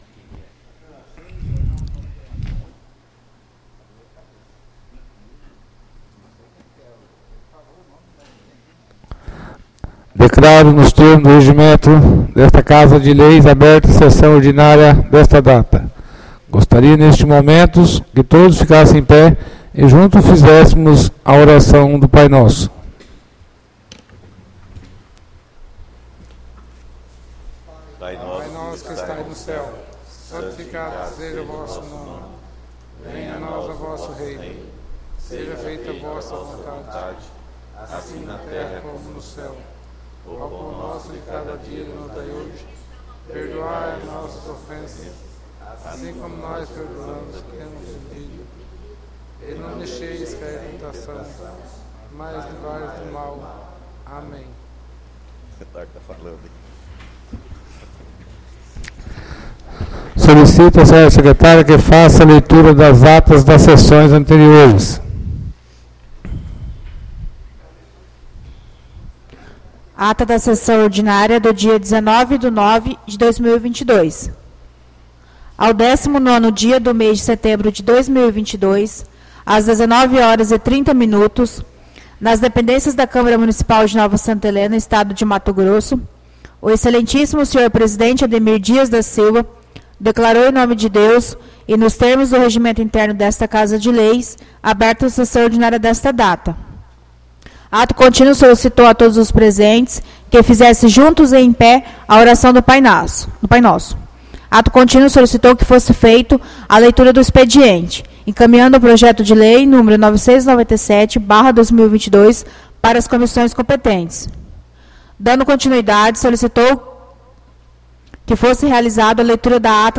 ÁUDIO SESSÃO 03-10-22 — CÂMARA MUNICIPAL DE NOVA SANTA HELENA - MT